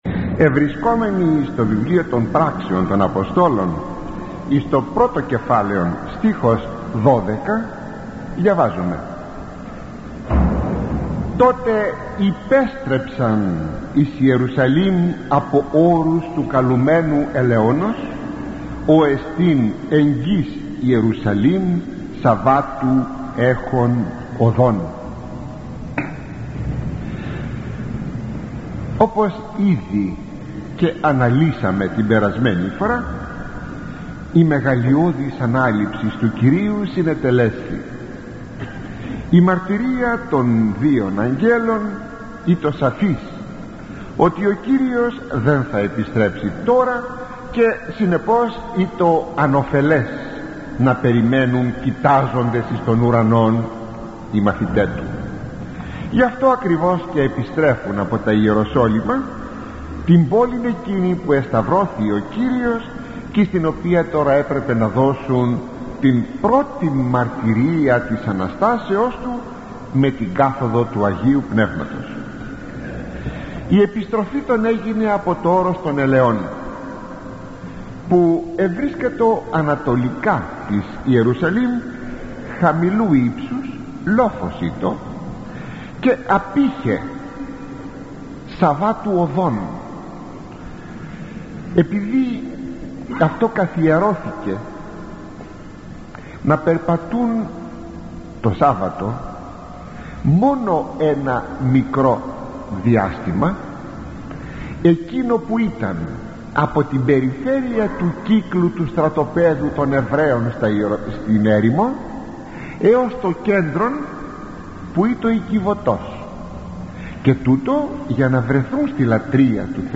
Αποσπάσματα από την 7η ομιλία